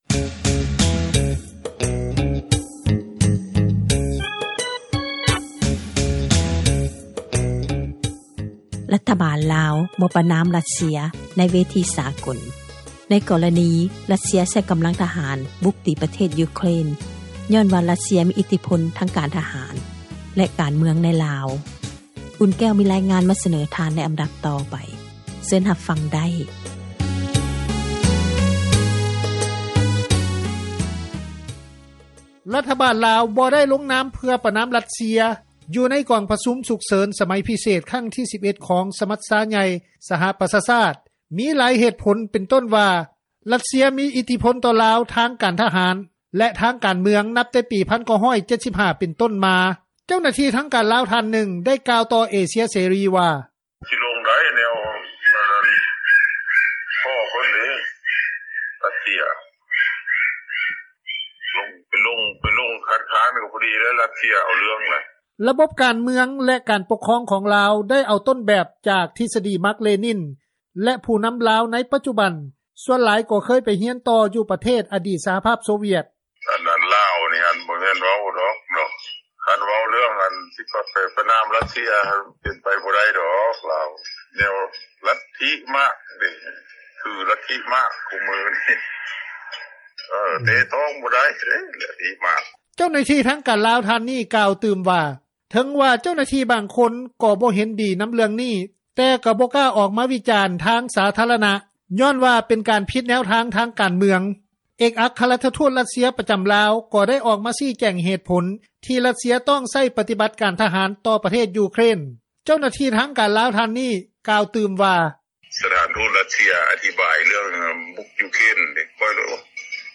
ນັກທຸລະກິດ ກ່າວວ່າ:
ພະນັກງານບຳນານ ທ່ານນຶ່ງ ໄດ້ໃຫ້ສຳພາດຕໍ່ເອເຊັຽເສຣີ ວ່າ: